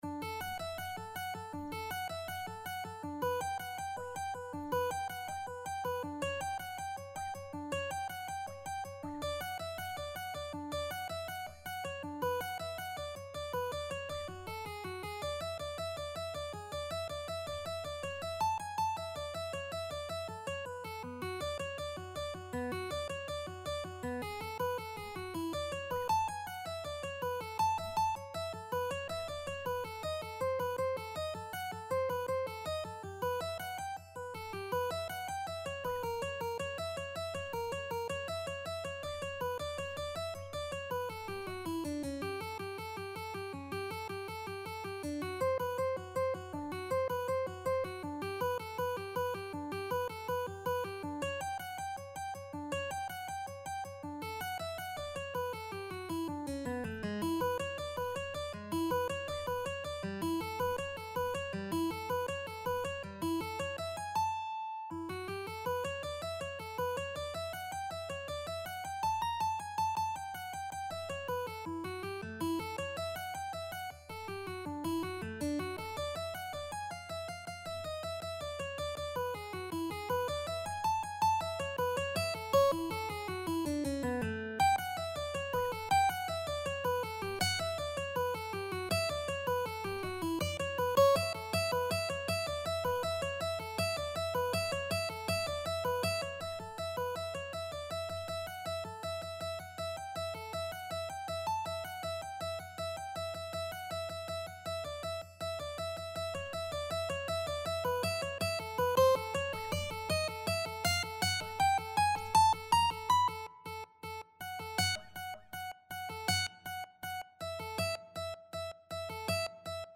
Mandolin version
Allegro = 80 (View more music marked Allegro)
4/4 (View more 4/4 Music)
Mandolin  (View more Advanced Mandolin Music)
Classical (View more Classical Mandolin Music)